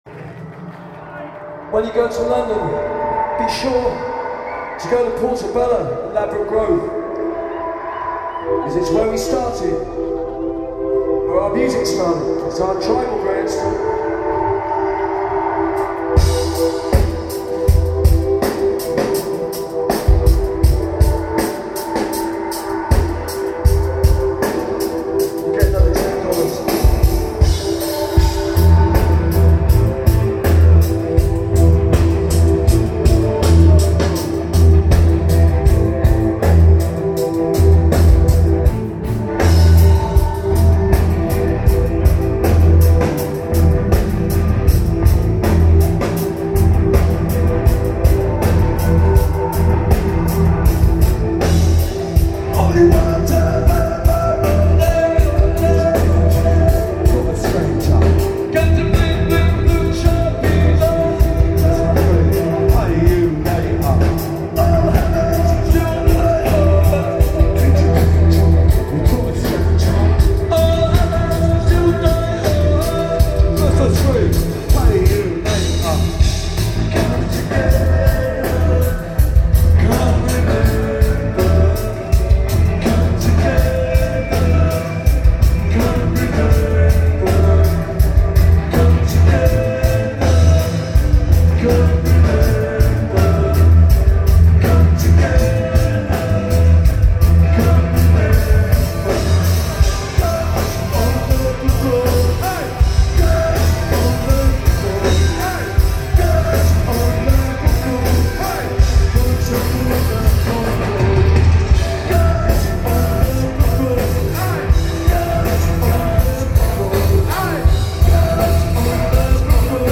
Irving Plaza 12/03/10